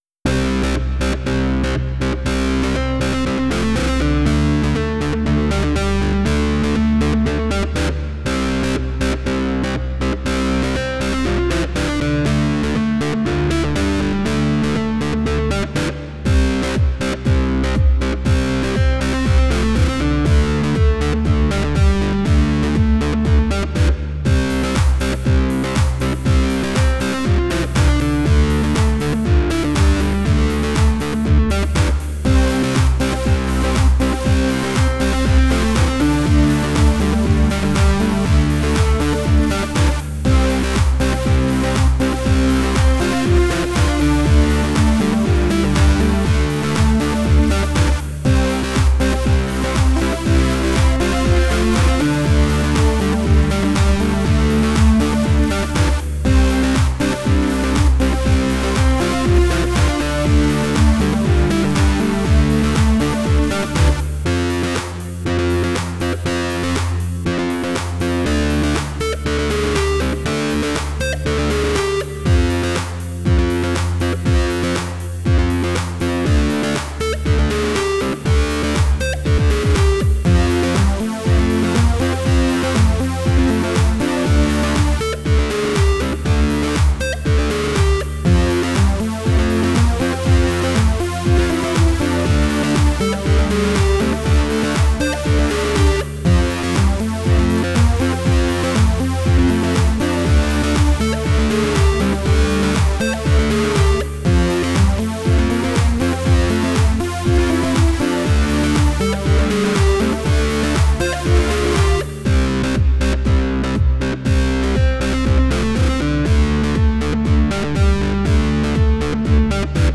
Tags: Guitar, Percussion, Digital